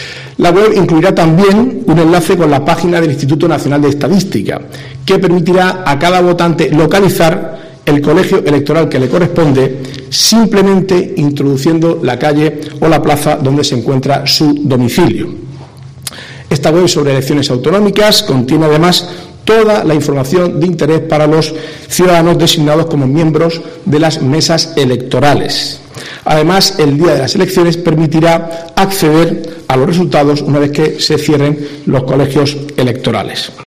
Marcos Ortuño, consejero de Presidencia, Turismo, Cultura, Juventud, Deportes y Portavocía
El consejero de Presidencia y portavoz del Ejecutivo, Marcos Ortuño, ha presentado esa web durante la rueda de prensa posterior a la sesión semanal del Consejo de Gobierno, y ha detallado que el portal incluye información sobre el censo electoral, las formas de ejercer el derecho al voto, los resultados de procesos electorales anteriores o un buscador de colegios electorales.